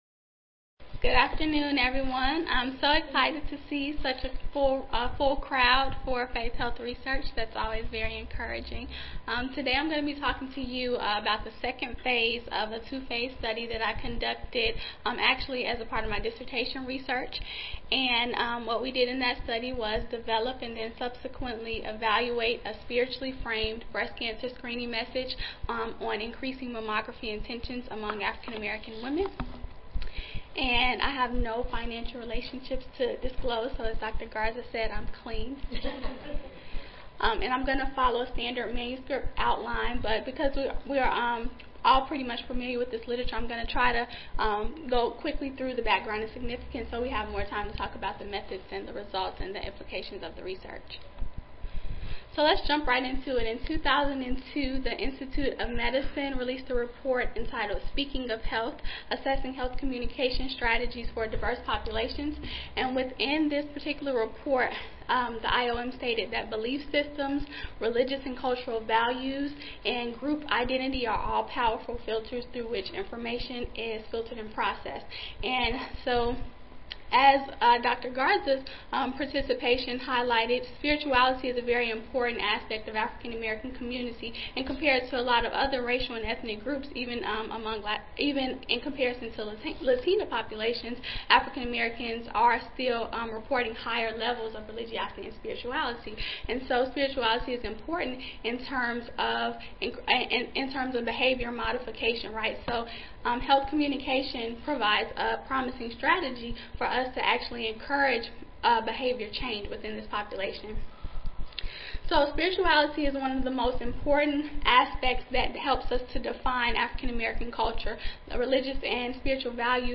recording Recorded Presentation